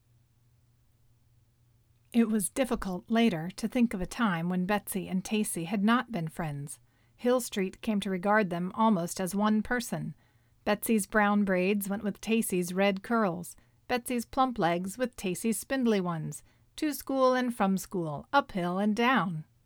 I could listen to a story in that voice.
Here is the updated clip WITH 2 seconds of room tone at the beginning.
There is what I think is a fan hummmmm in the background.